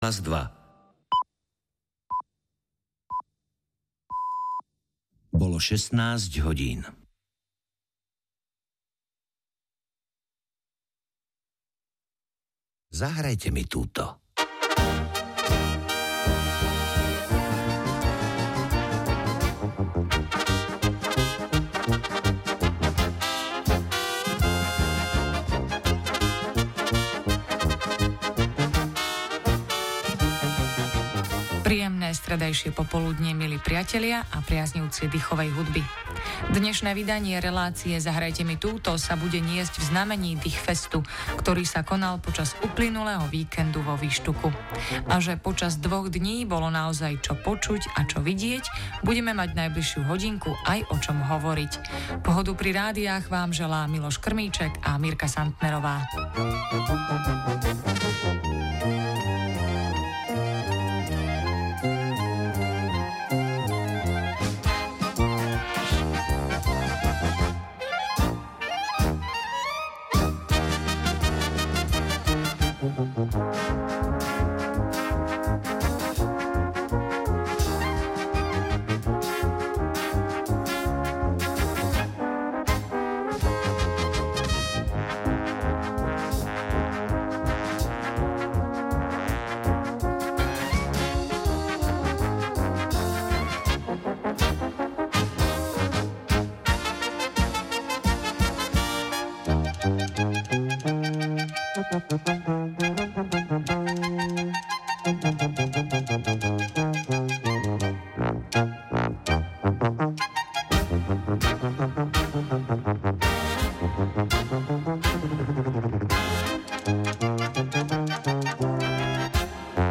Tu v celoslovenskom vysielaní Rádia Slovensko 1 budú naživo diskutovať a odpovedať na telefonáty poslucháčov na témy: letné festivaly dychovej hudby, minulosť, súčasnosť a budúcnosť dychoviek na Slovensku.